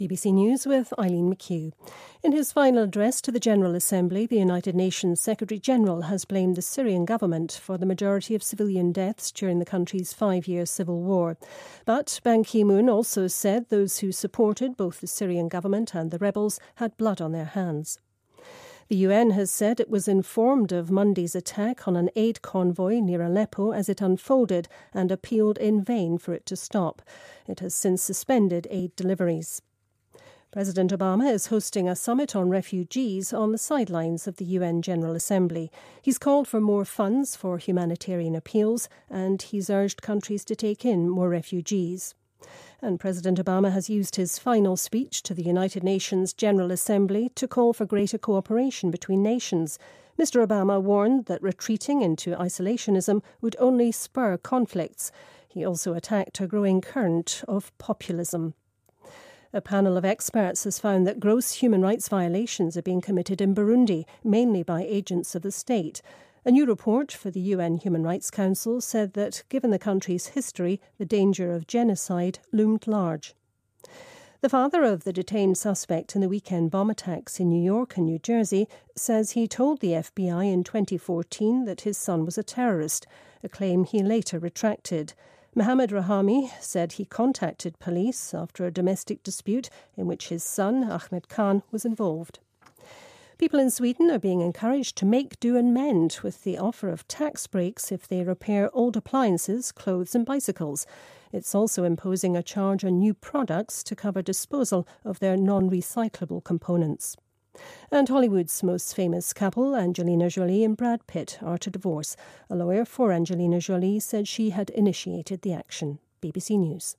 BBC news,好莱坞巨星皮特朱莉宣布离婚